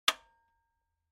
دانلود آهنگ رادیو 8 از افکت صوتی اشیاء
جلوه های صوتی